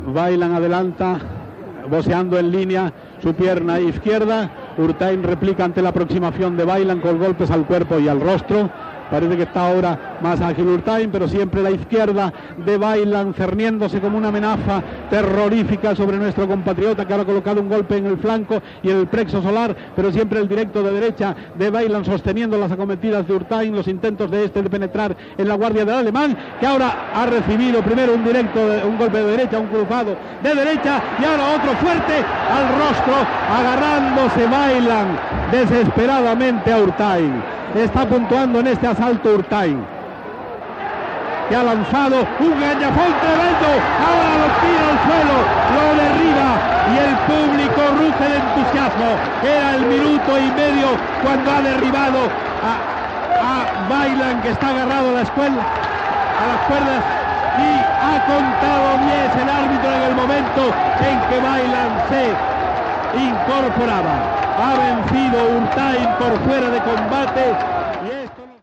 Narració del setè assalt.
Esportiu